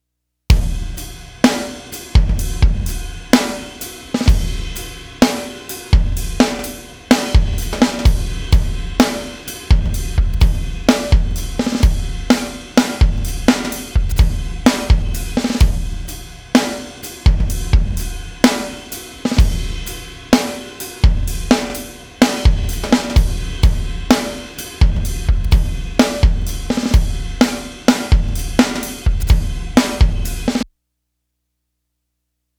As an example I hit the REC button & tracked two loose grooves with some imperfections pretty far off the grid. I then ran them thru an extreme rough & dirty uncleaned Beat Detective pass. You can hear how the bounce is stripped & creates a lifeless groove.
Loose Groove Two Over Quantized.wav
Also, there’s all those nasty artifacts…
Nice drumming + great sounding kit!
Loose-Groove-Two-Over-Quantized.wav